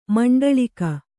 ♪ maṇḍaḷika